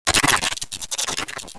growlm1.wav